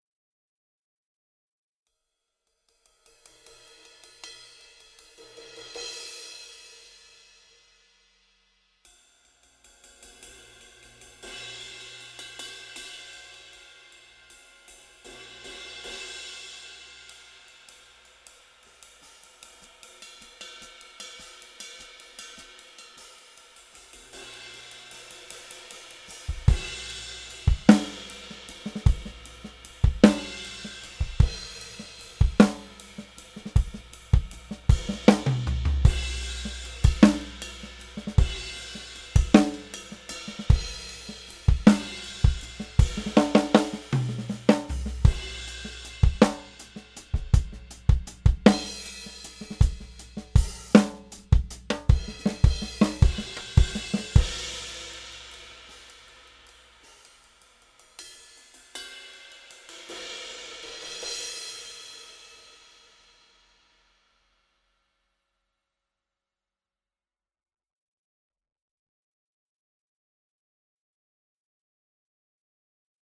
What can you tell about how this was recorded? Listen as We Test a Slew of New Mic Models From Audio-Technica Audio-Technica Mics